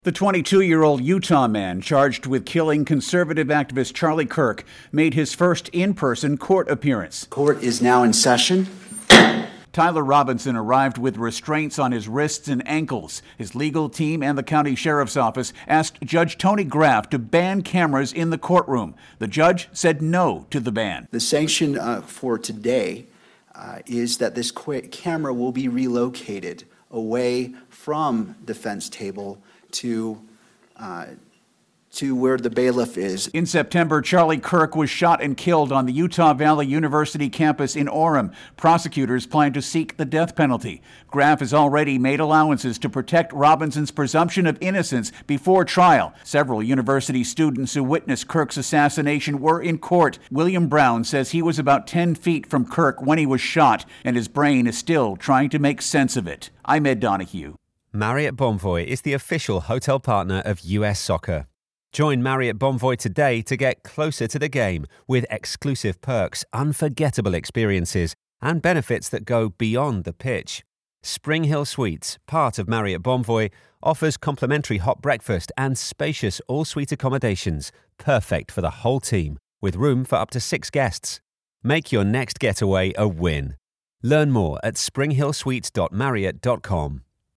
reports on court action the Charlie Kirk assassination case.